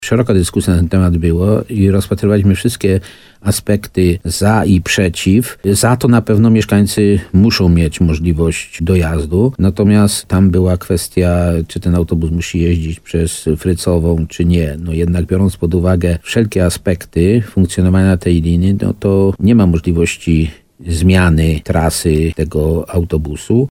Jak mówił w programie Słowo za Słowo na antenie RDN Nowy Sącz, wójt Nawojowej Stanisław Kiełbasa, połączenie jest potrzebne mieszkańcom, mimo że wywoływało pewne wątpliwości.